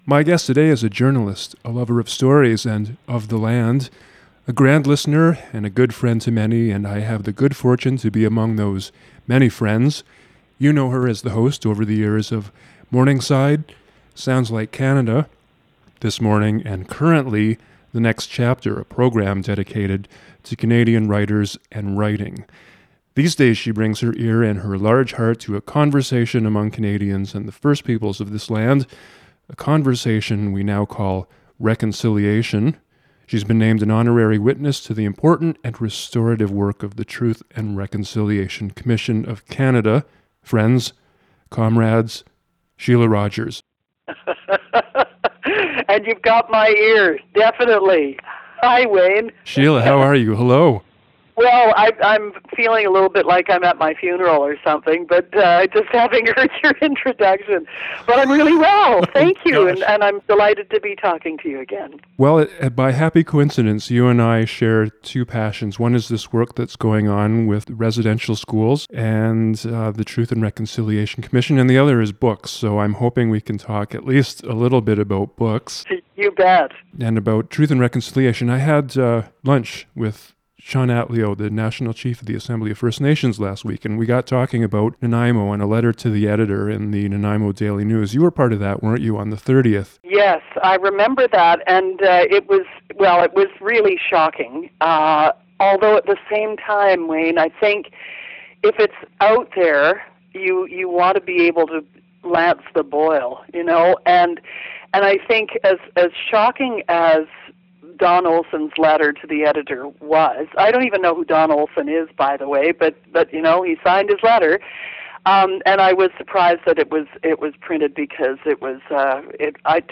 An Interview with Shelagh Rogers
shelagh-rogers-interview.mp3